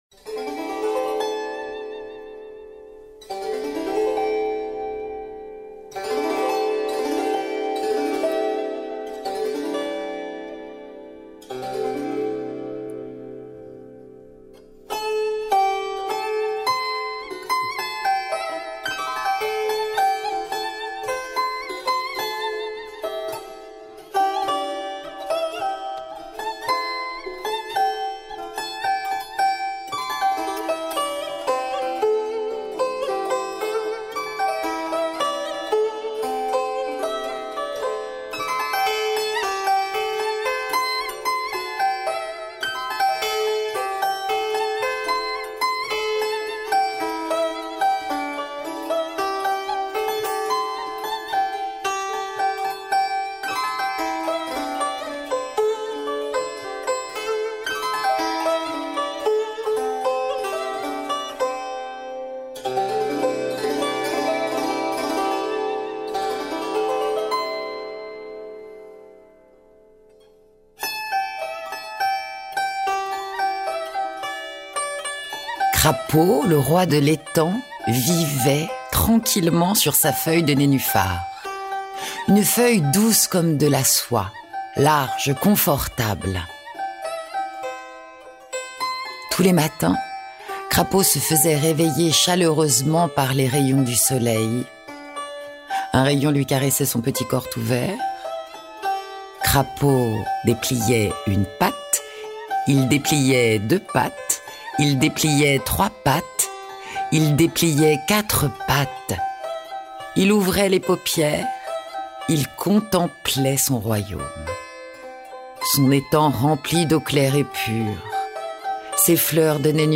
Contes d’auteurs